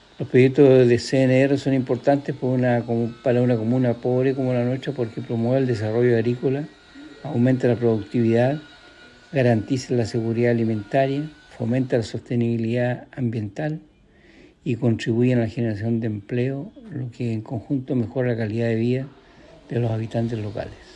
Proyecto-CNR-Corral-alcalde.mp3